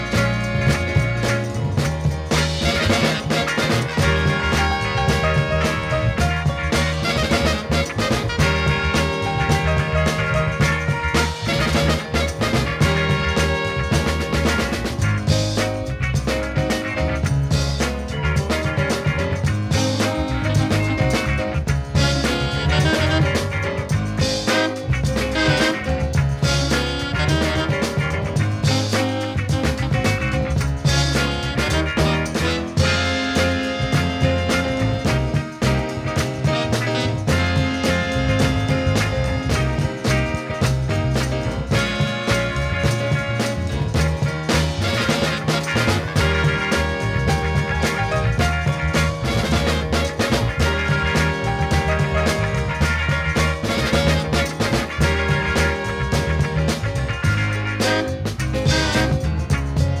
soulful dancers